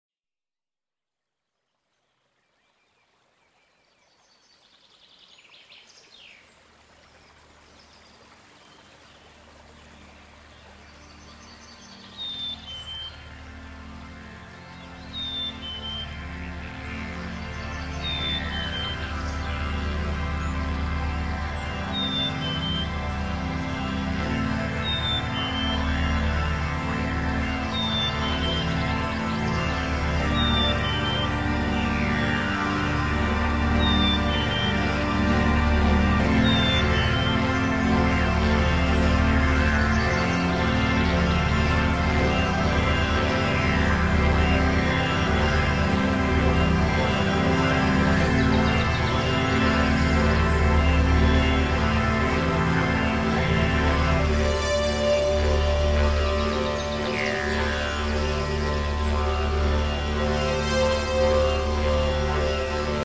DIDGERIDOO